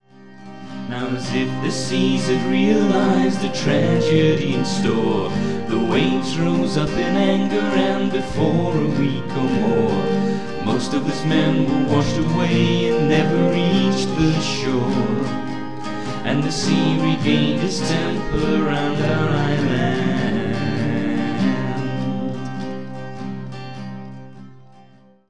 Ibanez PF60CENT1202 'semi' acoustic.
Bodrhan.
Quickshot mic (yes, really!)